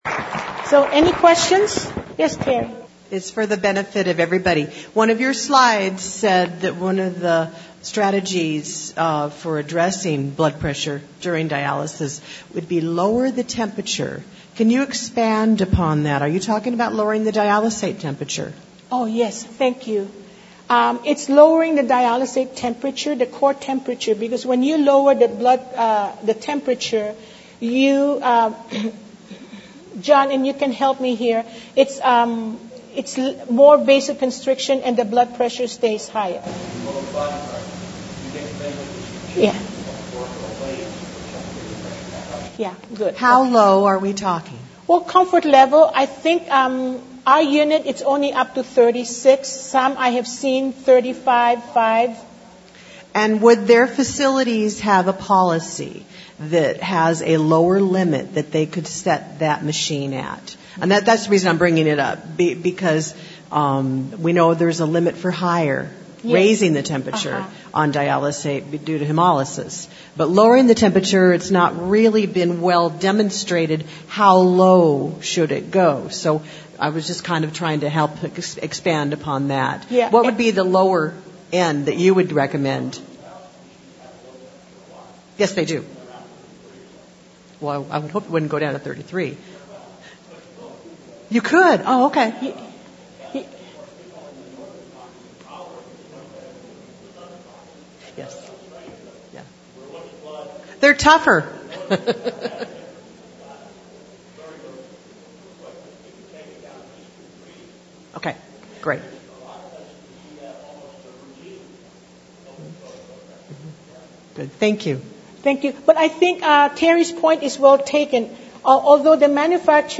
Questions And Answers